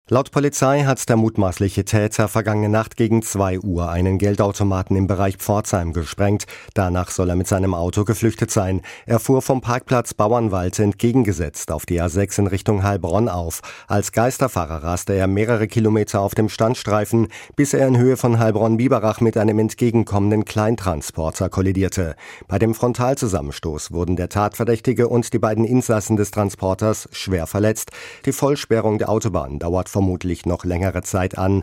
SWR-Reporter